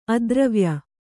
♪ adravya